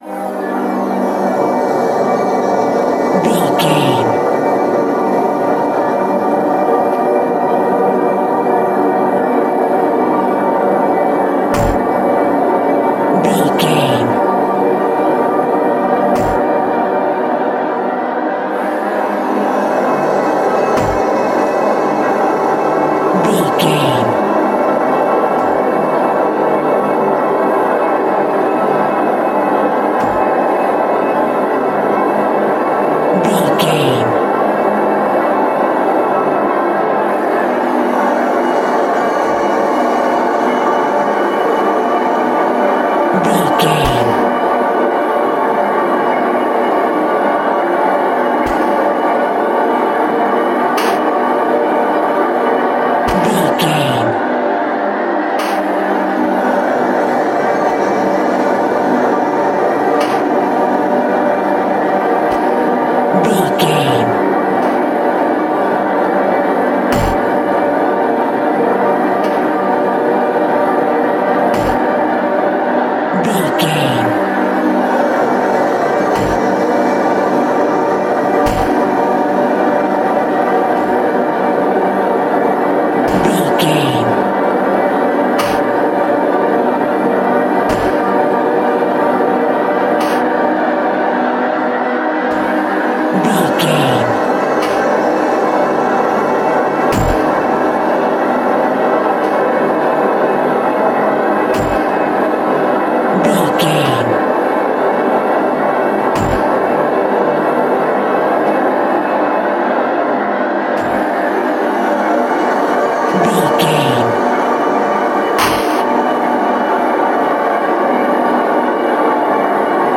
Glitchy Style Horror Sounds.
Atonal
ominous
eerie
synthesizer
Horror Ambience
Synth Pads
Synth Ambience